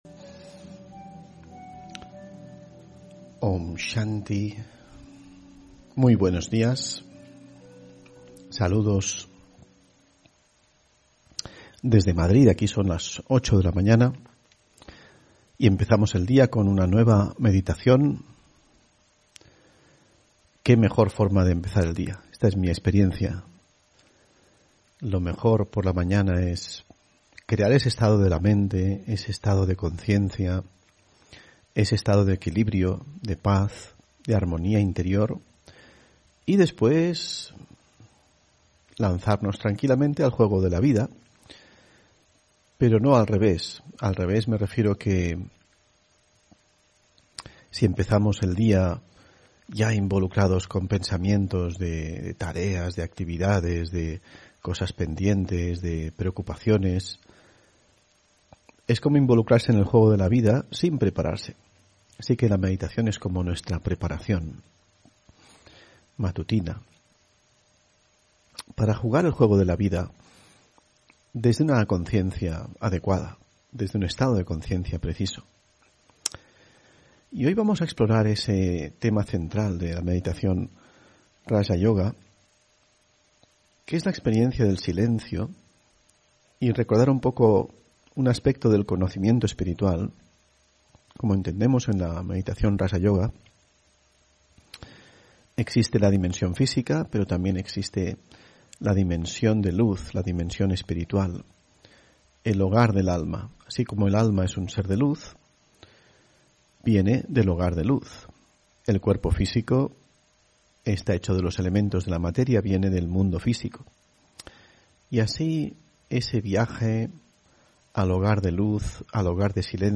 Meditación de la mañana: Viaje al hogar de luz